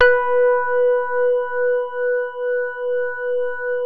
JAZZ SOFT H3.wav